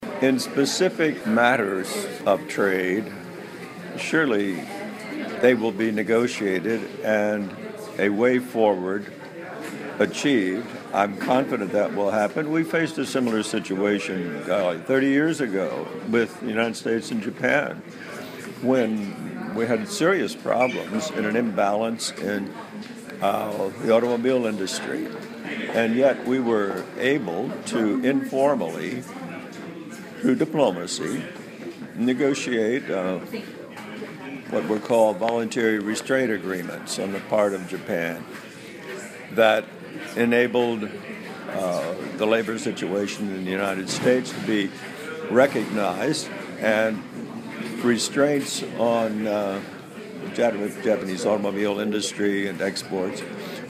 VOA专访里根国安顾问麦克法兰(4)